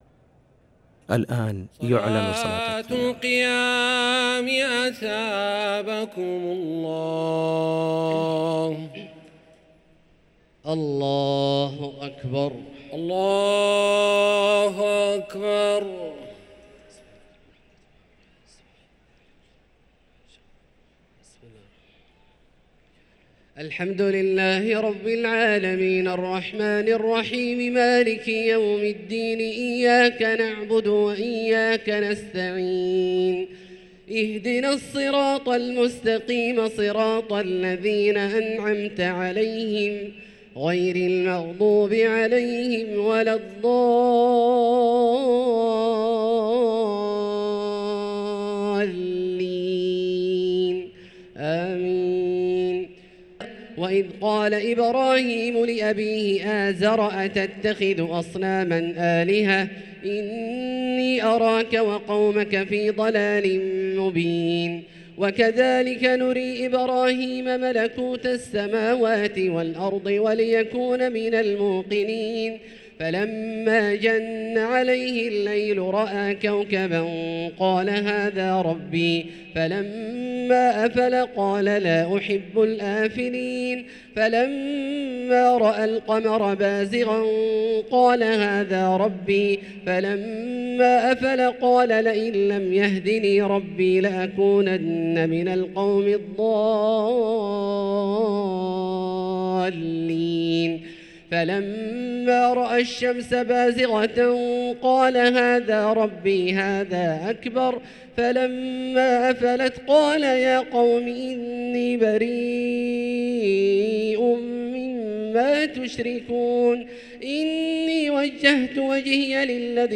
صلاة التراويح ليلة 10 رمضان 1444 للقارئ عبدالله الجهني - الثلاث التسليمات الأولى صلاة التراويح